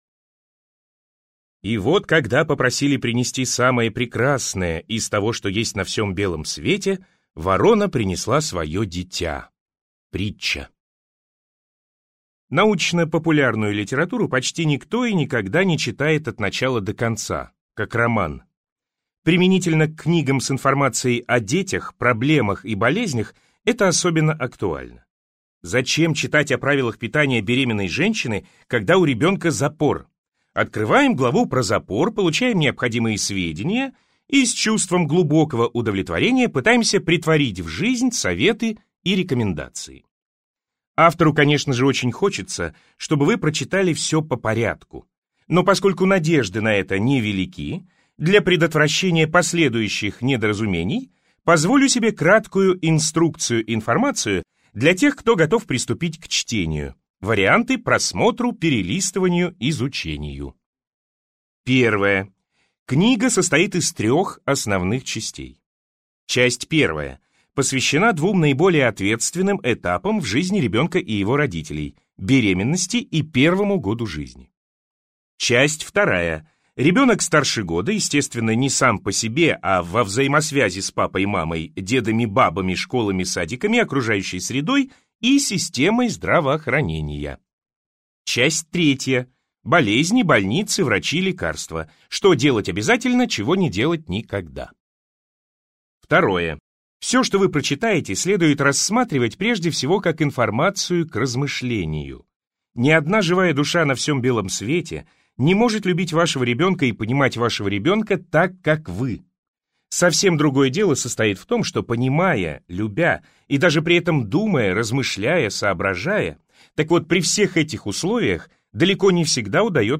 Аудиокнига Здоровье ребенка и здравый смысл его родственников (часть 1 и 2) | Библиотека аудиокниг